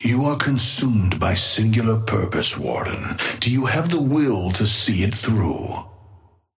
Amber Hand voice line - You are consumed by singular purpose, Warden. Do you have the will to see it through?
Patron_male_ally_warden_start_04.mp3